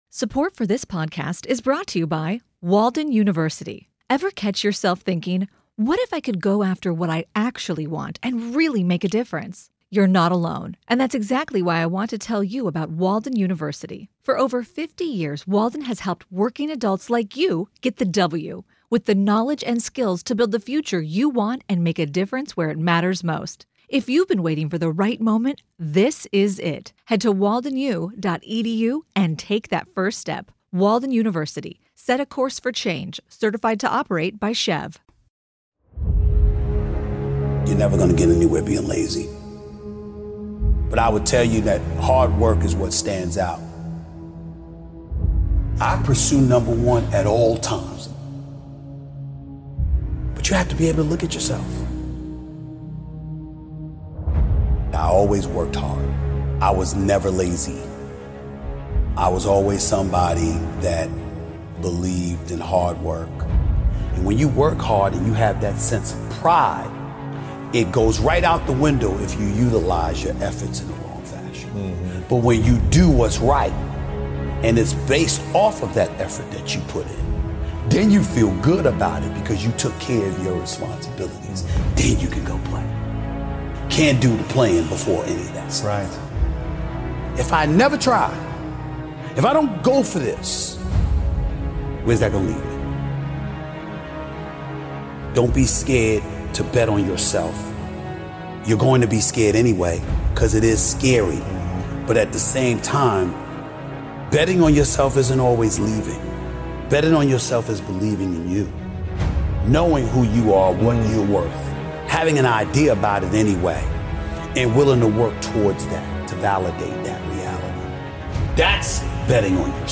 One of the Best Motivational Speeches Ever Featuring Stephen A. Smith.
Speaker: Stephen A. Smith Stephen is one of America's most popular sports media icons.